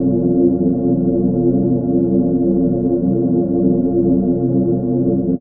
Index of /90_sSampleCDs/Chillout (ambient1&2)/13 Mystery (atmo pads)
Amb1n2_y_pad_f.wav